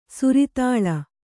♪ suritāḷa